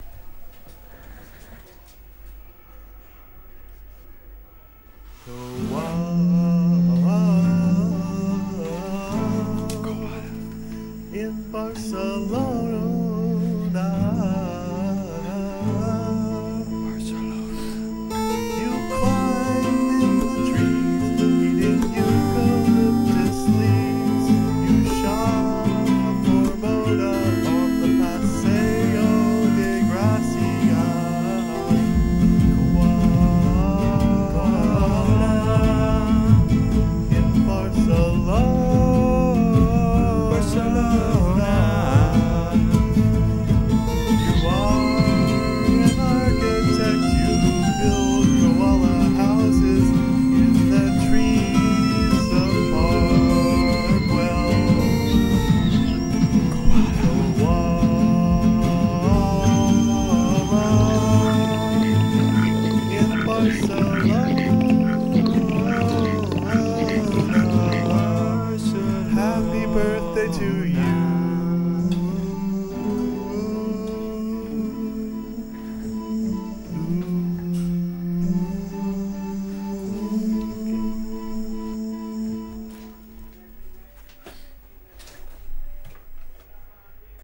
This was recorded in November, for my girlfriend's birthday.
guitar and whisper vocals
Filed under: Song | Comments (2)